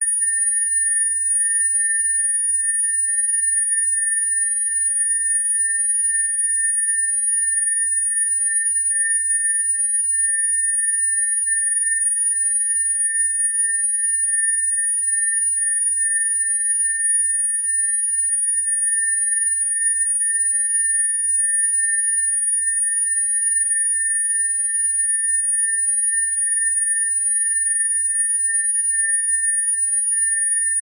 Звон в ушах специальный высокочастотный тон 1790 Гц